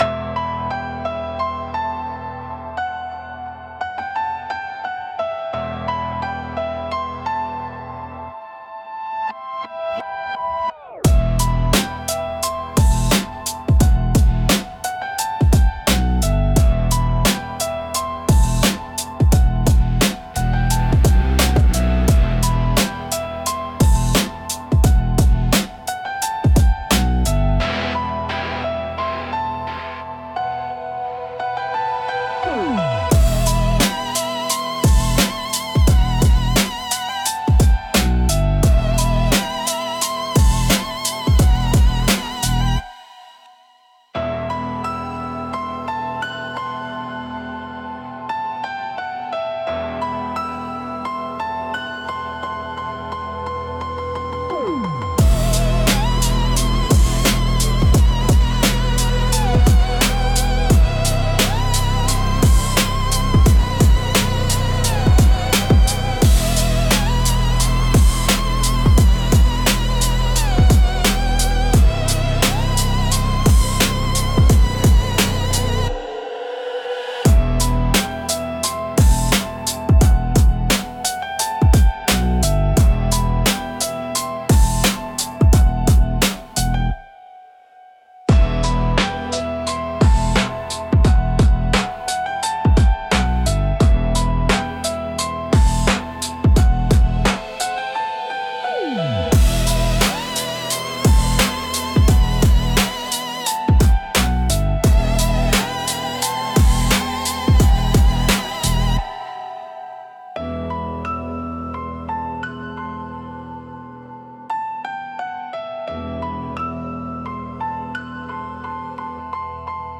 Instrumental - Sample & Survive